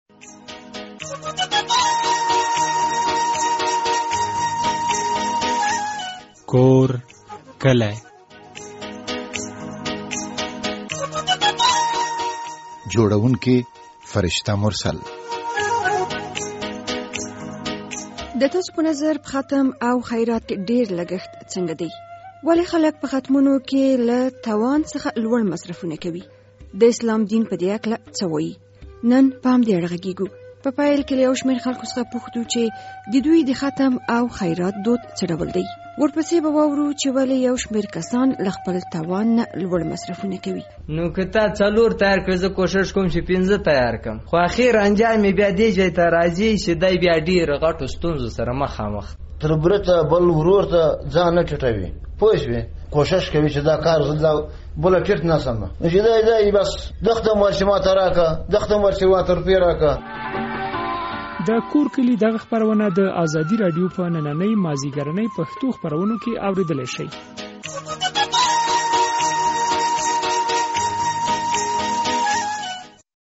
• د دې خپرونې په پیل کې له یو شمېر خلکو څخه پوښتو چې د دوی د ختم او خیرات دود څه ډول دی؟
له دیني عالم سره مو هم مرکه کړې.